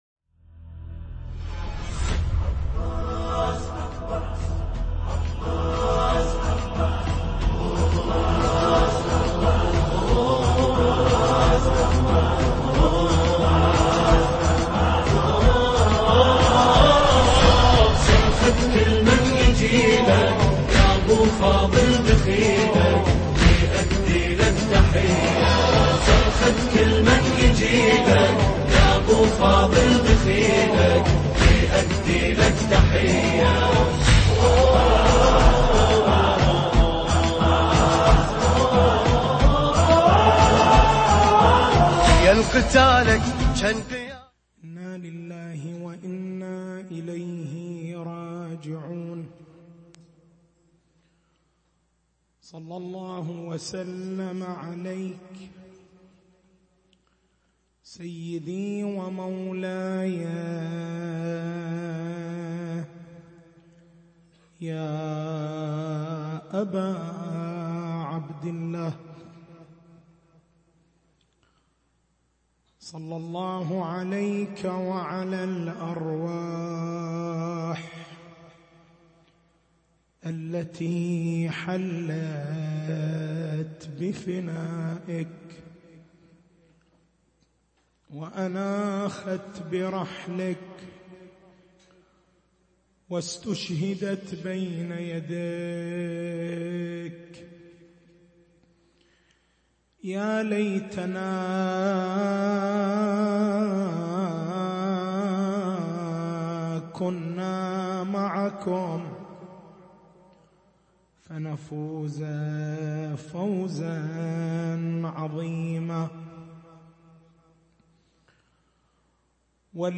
تاريخ المحاضرة: 12/01/1440 محور البحث: تحليل ما ورد عن الإمام الصادق (ع): ((علماء شيعتنا مرابطون في الثغر الذي يلي إبليس وعفاريته، ليمنعوهم من الخروج على ضعفاء شيعتنا، وعن أن يتسلّط عليهم إبليس وشيعته النواصب)).